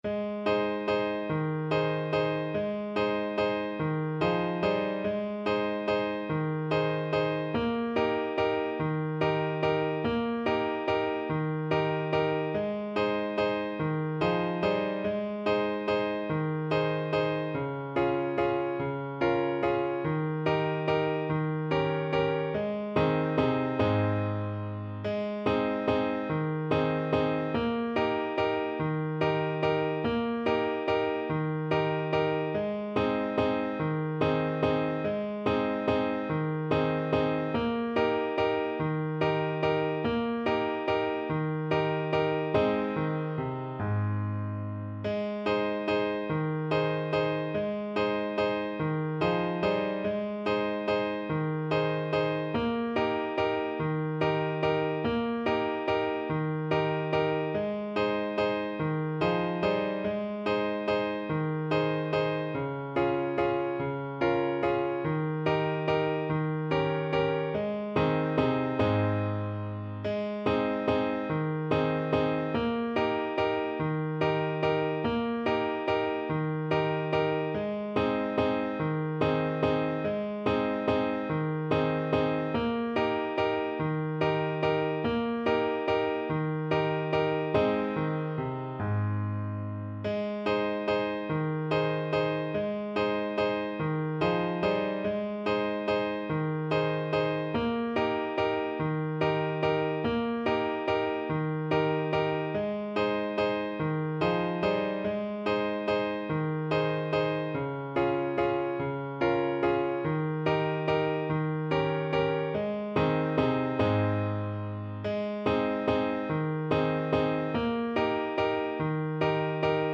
Steady one in a bar .=c.48
3/8 (View more 3/8 Music)
Traditional (View more Traditional French Horn Music)